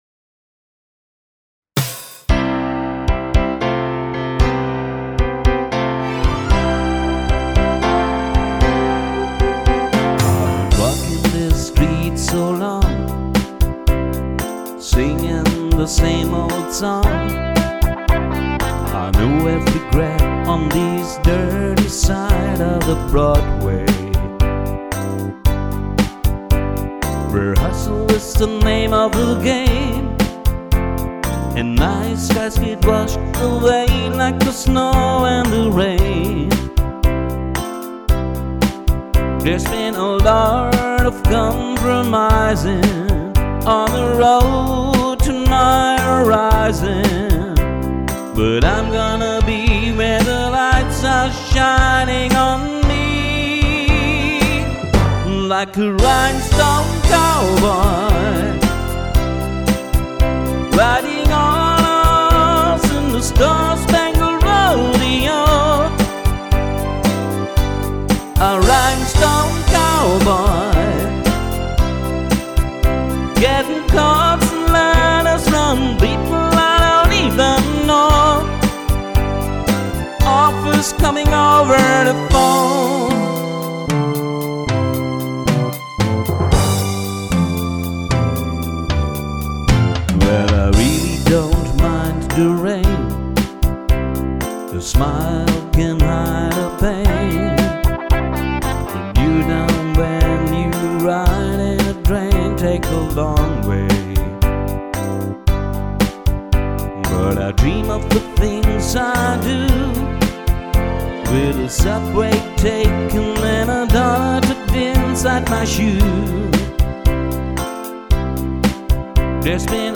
• Country
• Coverband